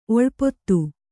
♪ oḷpottu